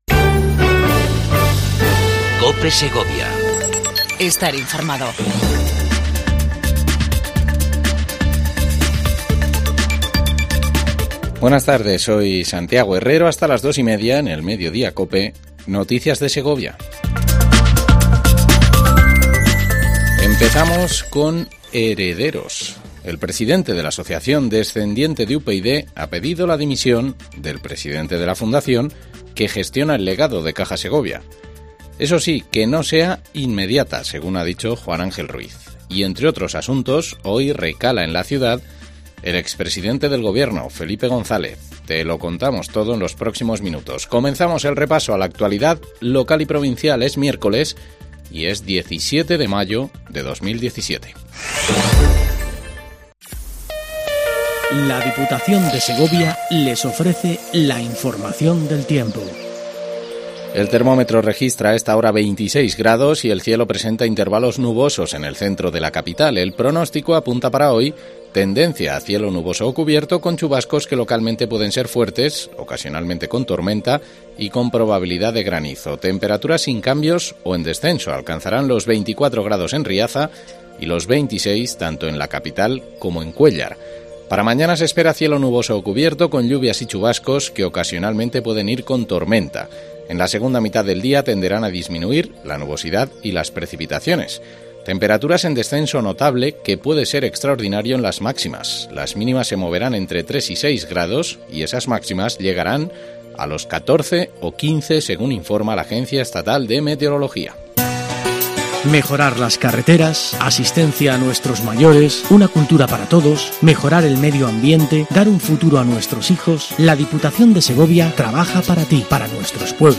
INFORMATIVO MEDIODIA COPE EN SEGOVIA 17 05 17